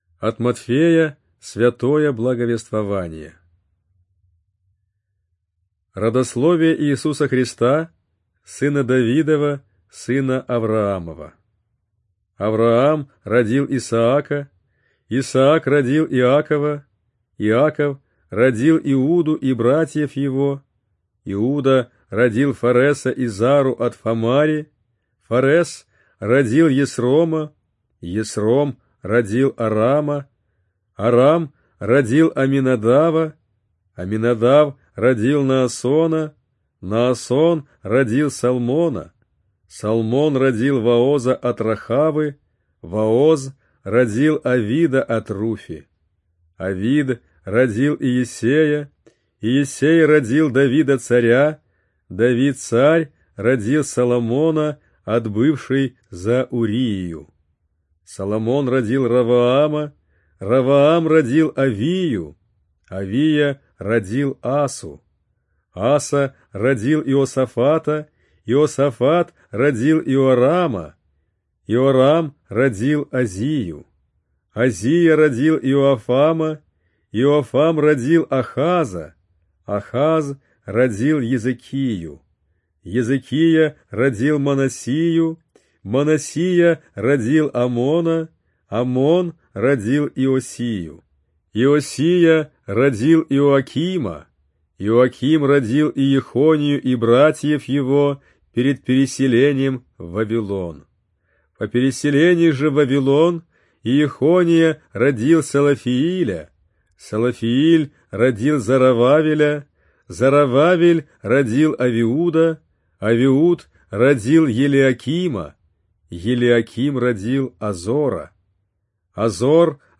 Аудиокнига Евангелие на русском языке | Библиотека аудиокниг